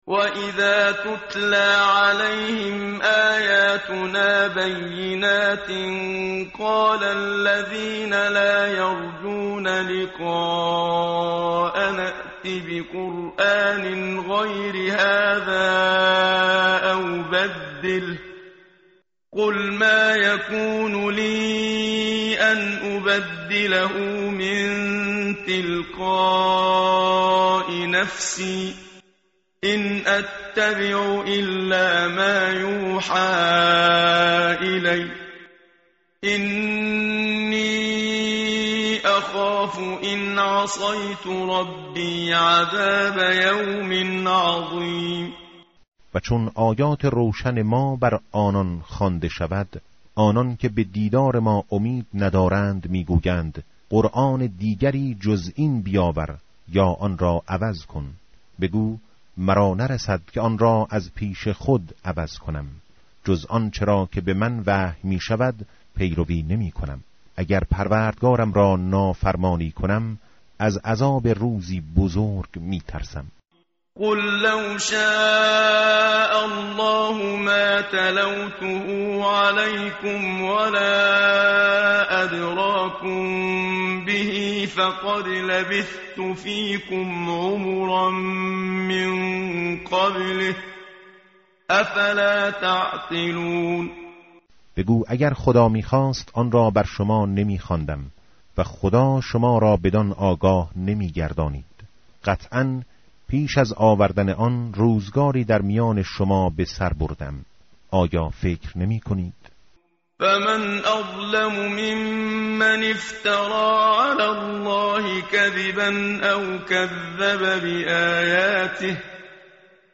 متن قرآن همراه باتلاوت قرآن و ترجمه
tartil_menshavi va tarjome_Page_210.mp3